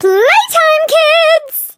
flea_ulti_vo_08.ogg